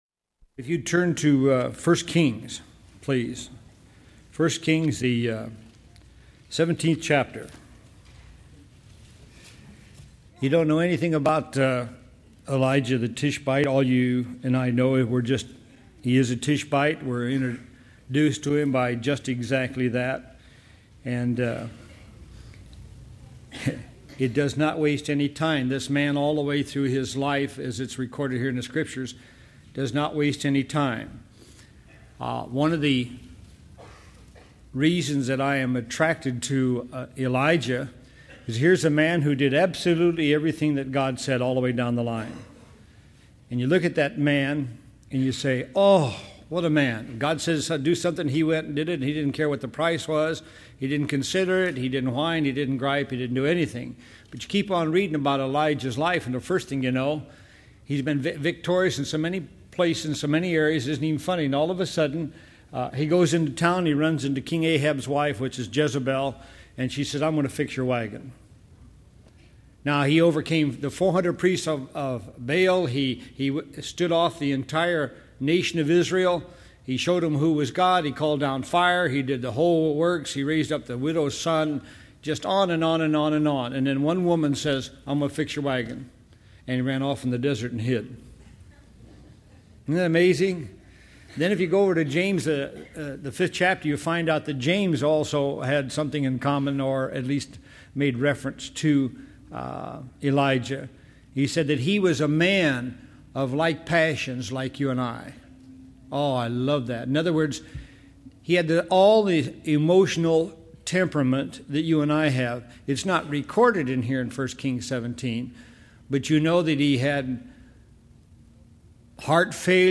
The Lord Will Supply download sermon mp3 download sermon notes Welcome to Calvary Chapel Knoxville!